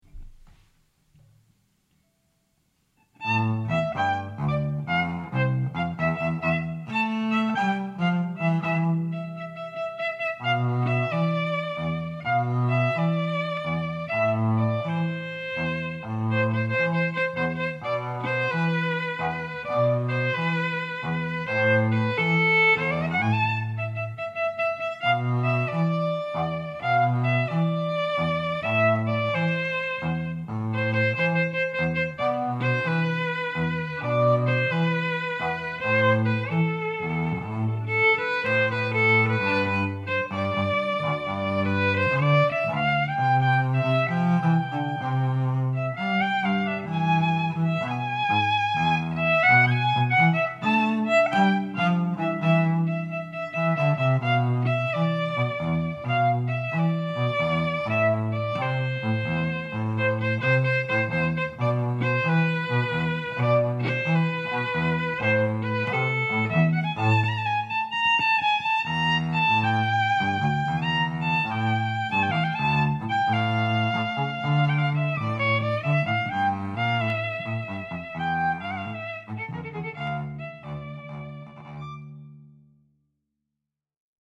ELECTRIC DUO
The professional string ensemble for your event.